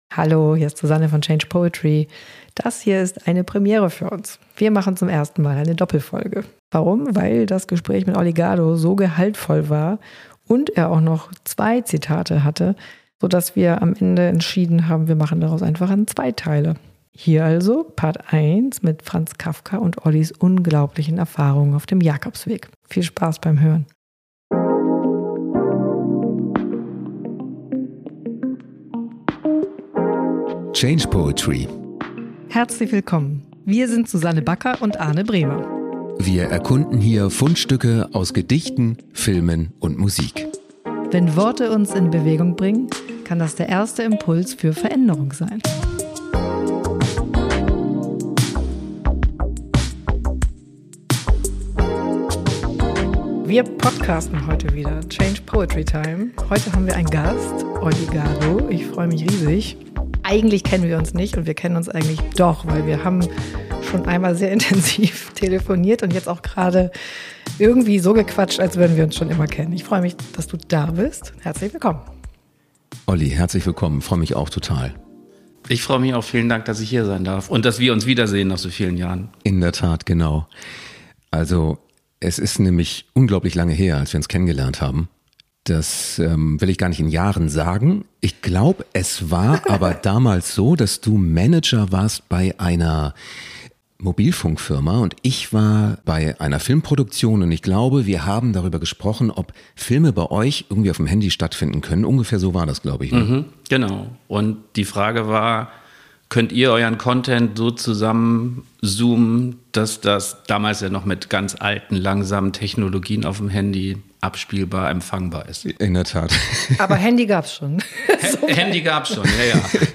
Ein Change-Poetry-Gespräch über Mut, Verarbeitung und die Kraft des ersten Schritts!